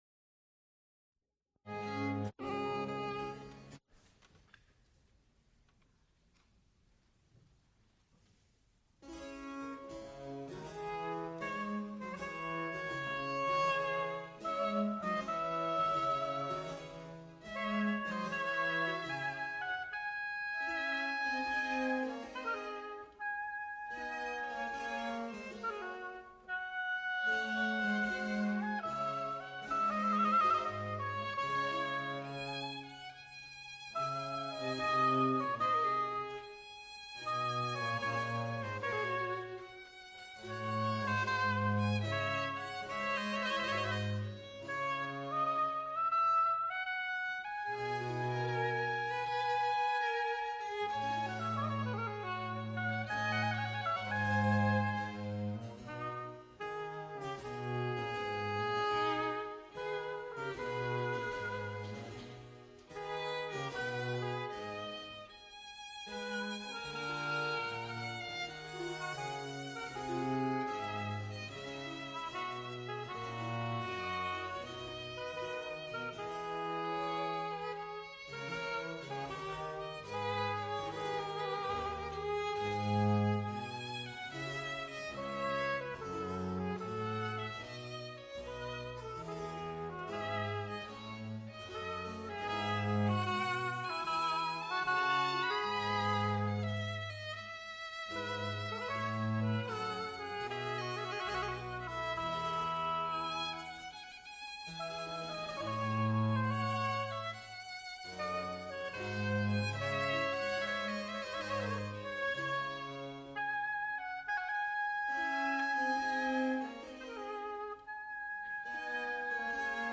Recorded live February 28, 1978, Frick Fine Arts Auditorium, University of Pittsburgh.
musical performances
Trio sonatas (Oboe, violin, continuo)